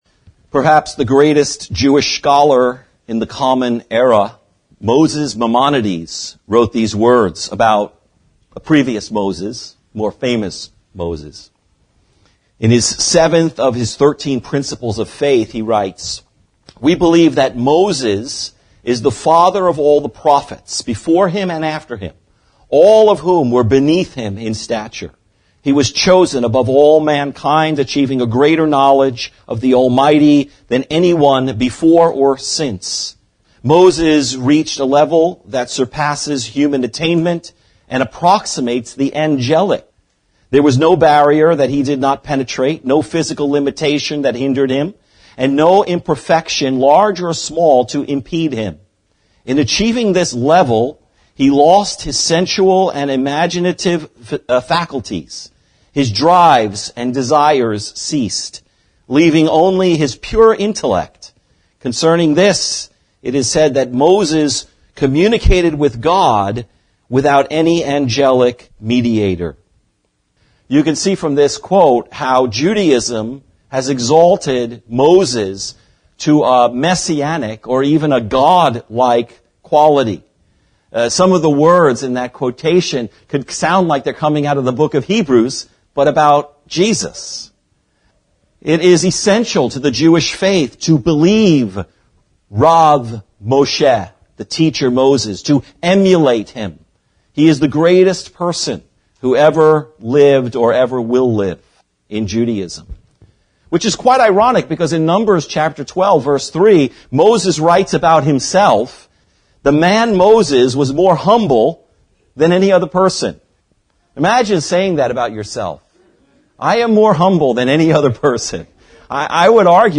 The Faith of Moses | SermonAudio Broadcaster is Live View the Live Stream Share this sermon Disabled by adblocker Copy URL Copied!